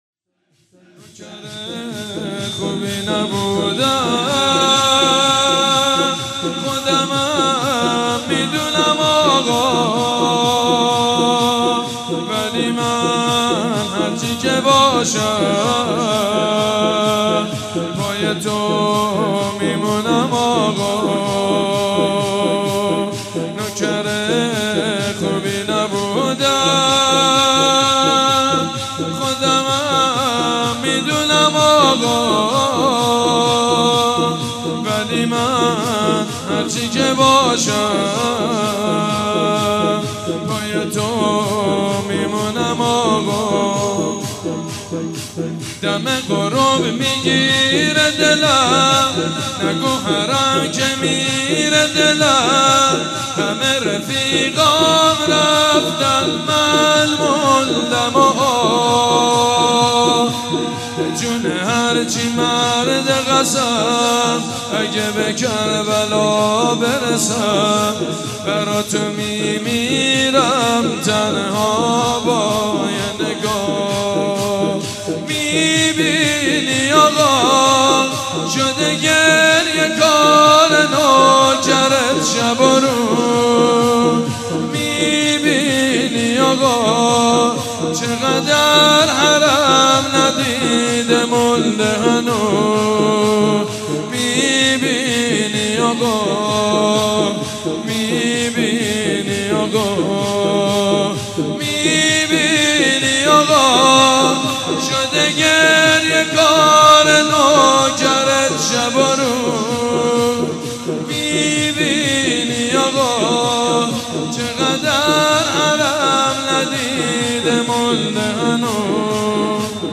مداحی شنیدنی حاج سید مجید بنی فاطمه درباره زائران اربعین